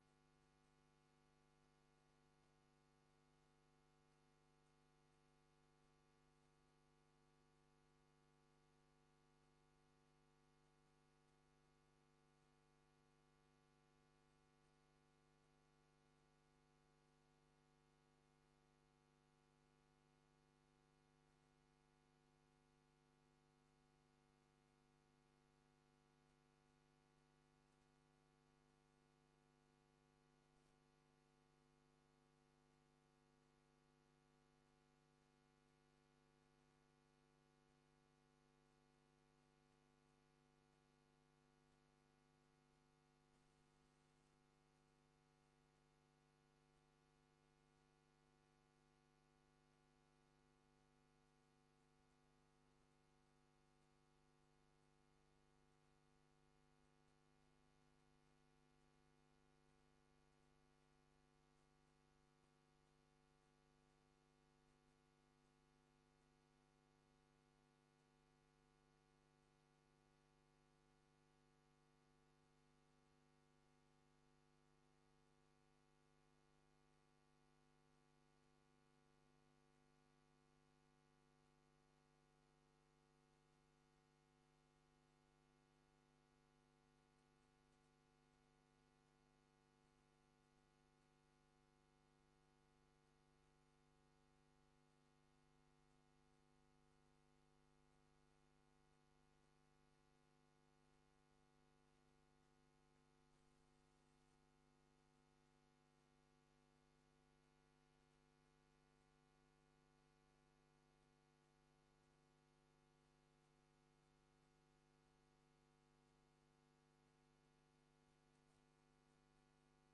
Download de volledige audio van deze vergadering
Locatie: Raadszaal